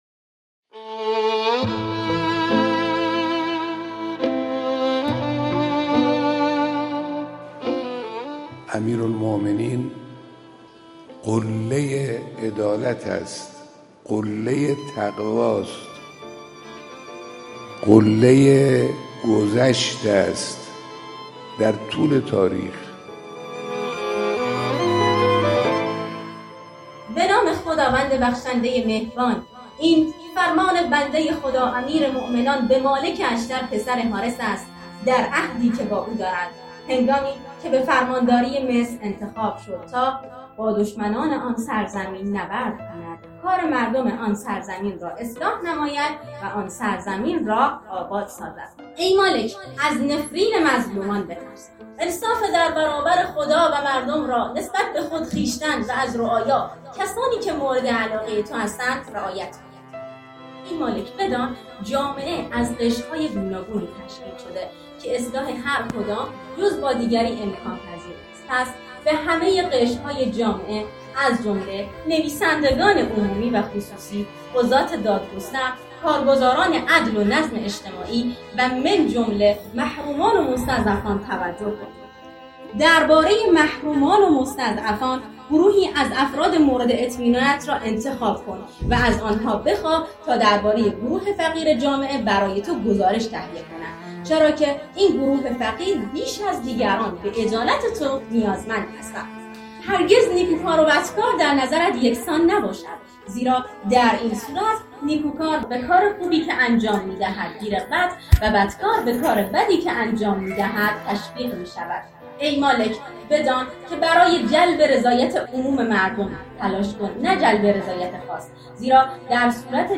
در جریان این پویش از دانشجویان، استادان و کارکنان خواسته شد که از هر نامه، کلام و خطبه‌های نهج‌البلاغه را که دوست دارند با صدا خود خوانده و ارسال کنند.